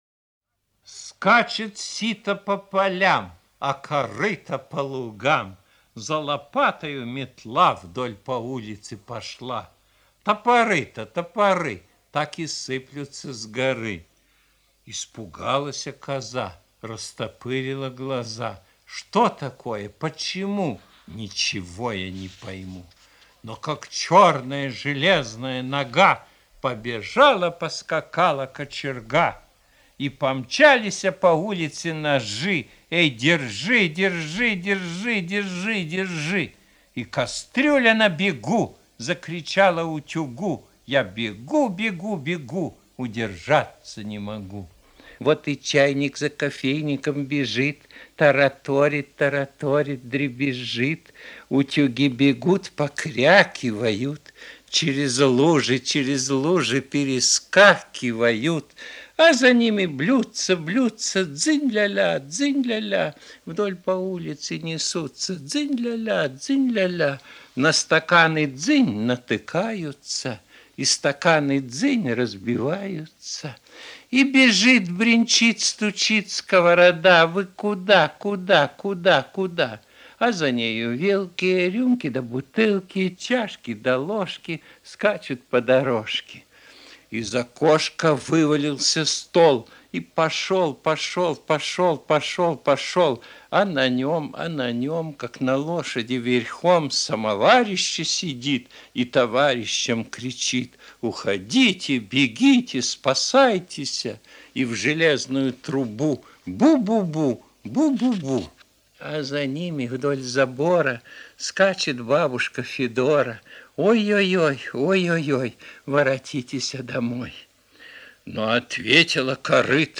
• Исполнители: Корней Чуковский